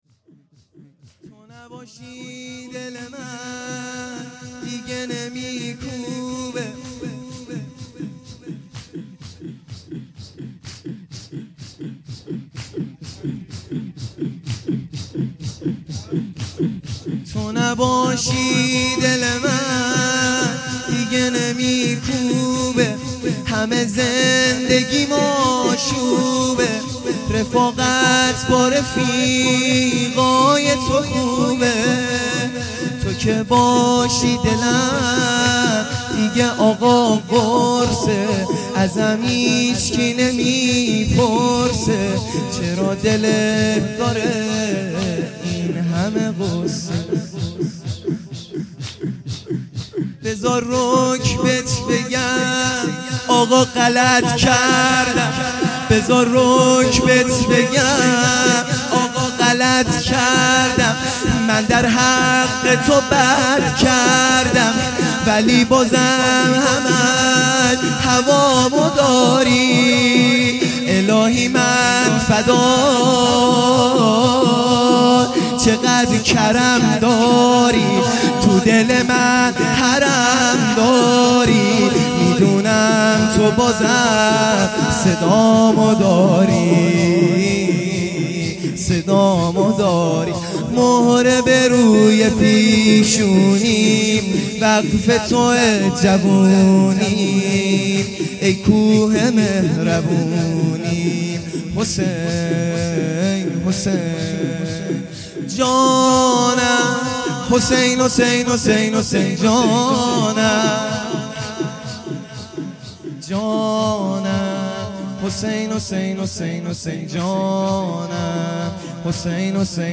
شور
شب دوم محرم الحرام